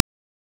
SFX / Clicks